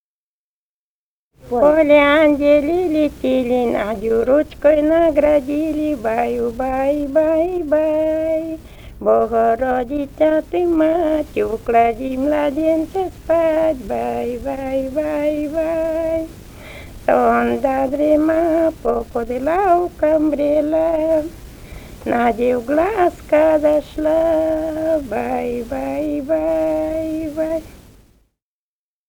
«В поле ангелы летели» (колыбельная).